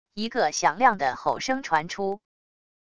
一个响亮的吼声传出wav音频